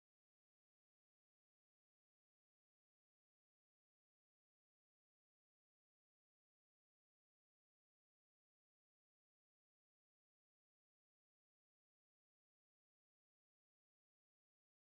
Balladen: Der Mutter Warnung
Tonart: D-Dur
Taktart: 3/4
Tonumfang: Oktave
Besetzung: vokal